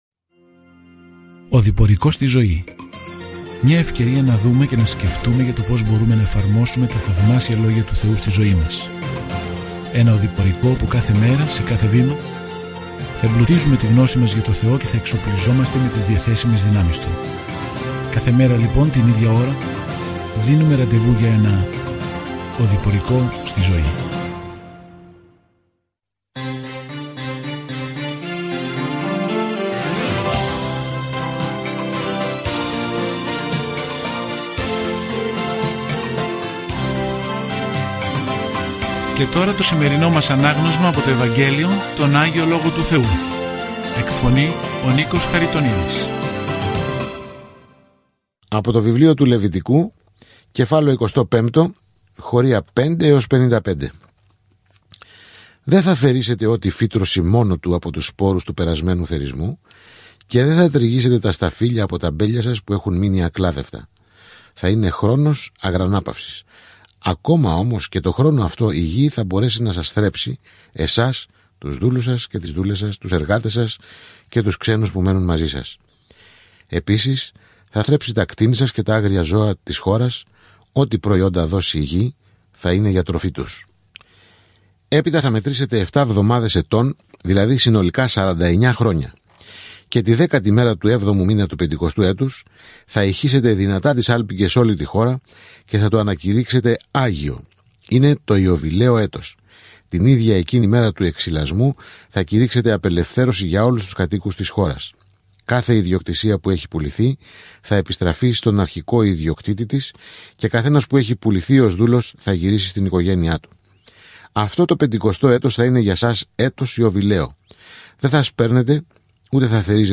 Κείμενο ΛΕΥΙΤΙΚΟΝ 25:5-55 Ημέρα 22 Έναρξη αυτού του σχεδίου Ημέρα 24 Σχετικά με αυτό το σχέδιο Πώς πρέπει να προσεγγίσουμε έναν άγιο Θεό; Με λατρεία, θυσίες και ευλάβεια, ο Λευιτικός απαντά σε αυτή την ερώτηση για τον αρχαίο Ισραήλ. Καθημερινά ταξιδεύετε στο Λευιτικό καθώς ακούτε την ηχητική μελέτη και διαβάζετε επιλεγμένους στίχους από το λόγο του Θεού.